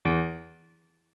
MIDI-Synthesizer/Project/Piano/20.ogg at 51c16a17ac42a0203ee77c8c68e83996ce3f6132